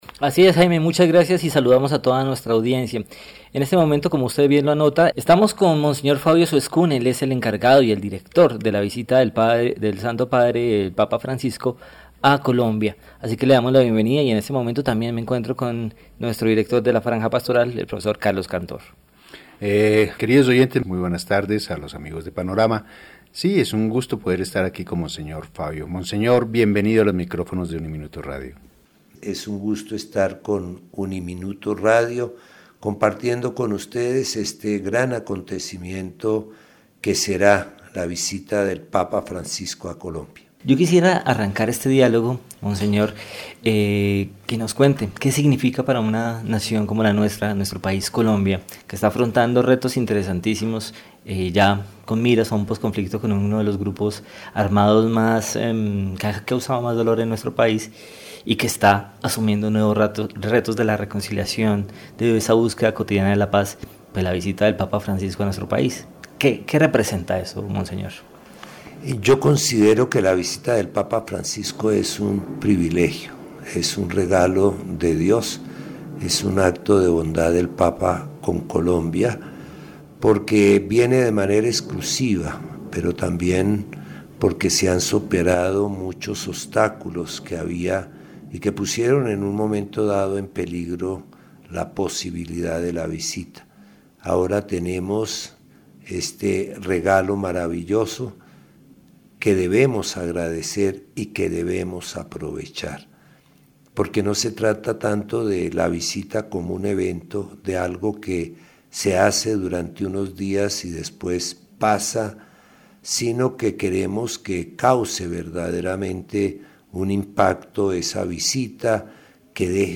En exclusiva para UNIMINUTO Radio estuvo Monseñor Fabio Suescún, encargado de organizar la visita del papa Francisco a Colombia el próximo mes de septiembre. Nos contó toda la agenda y detalles de esta histórica visita.